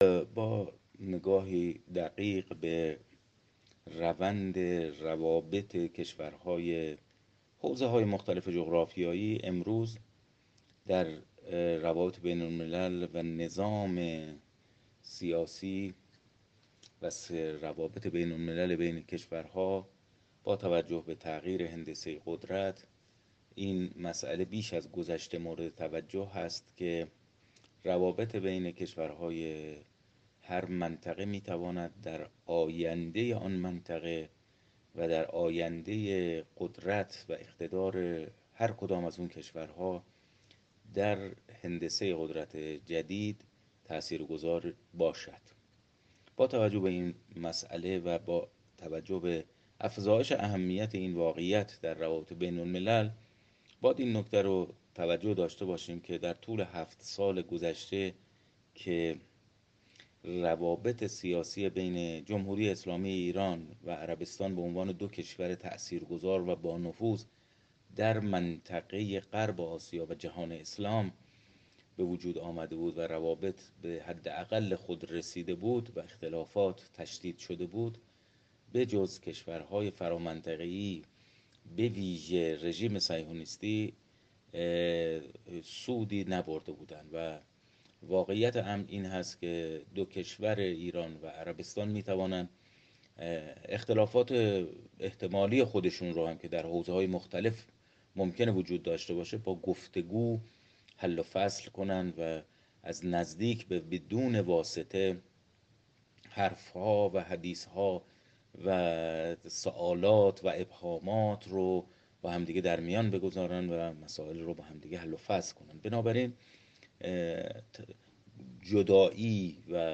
کارشناس مسائل غرب آسیا
گفت‌وگو